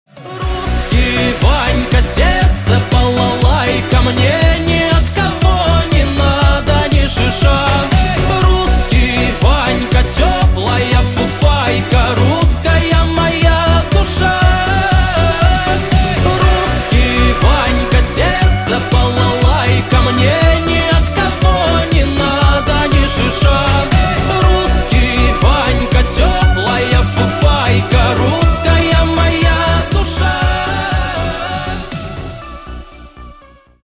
Поп Рингтоны
Мужские Рингтоны